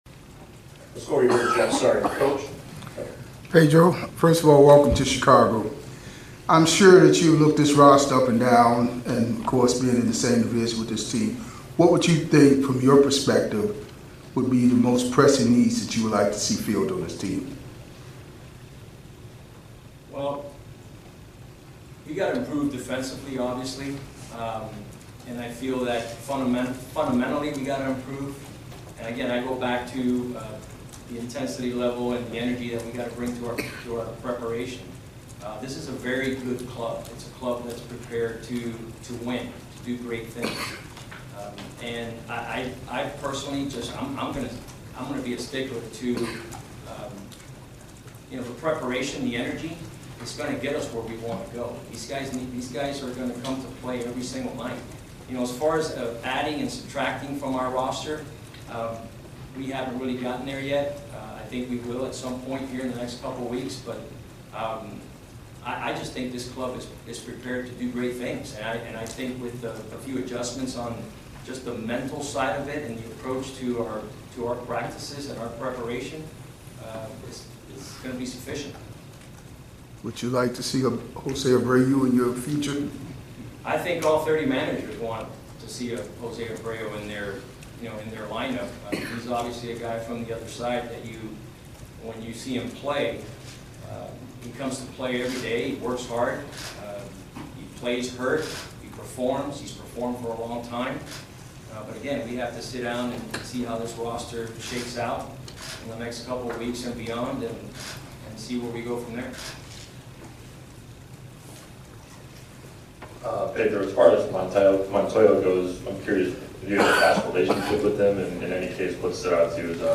Classic MLB interviews